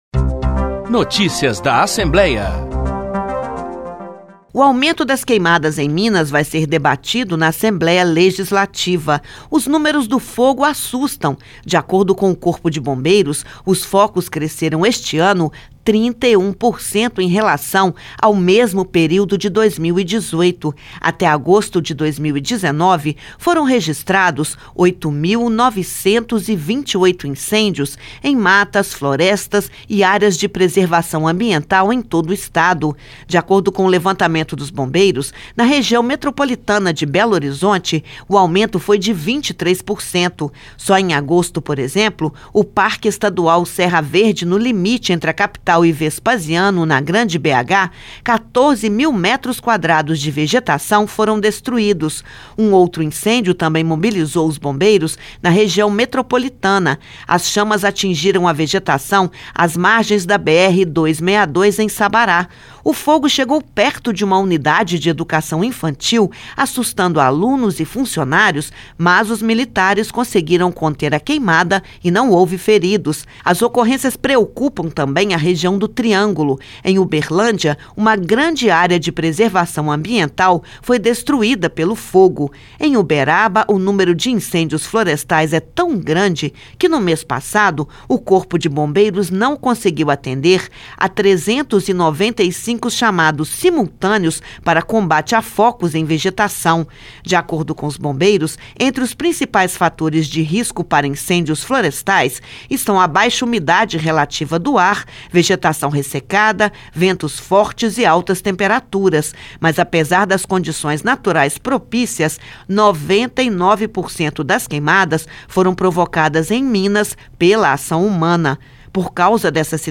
Em entrevista coletiva, concedida nesta quarta-feira (28/8/2019), na Assembleia Legislativa de Minas Gerais (ALMG), o presidente da Casa, deputado Agostinho Patrus (PV), manifestou a sua preocupação com o aumento das queimadas no Estado.